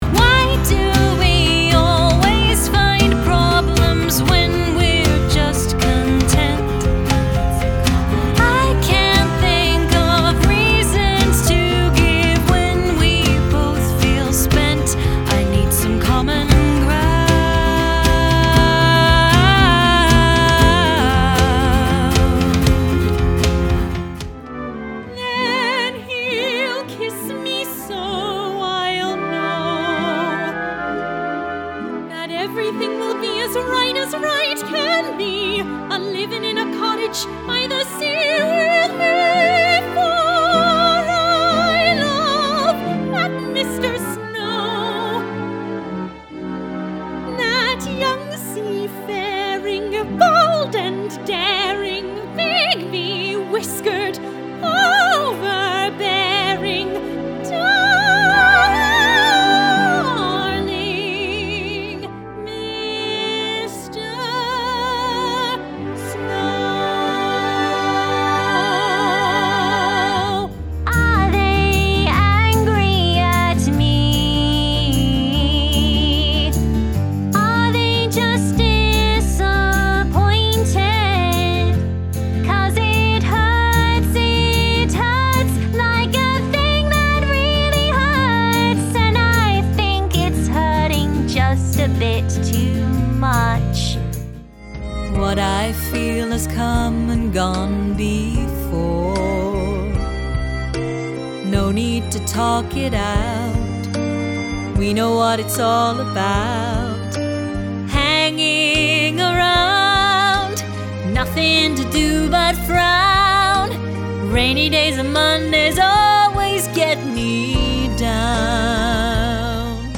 Singing Showreel
Female
British RP
Bright
Friendly
Playful
Youthful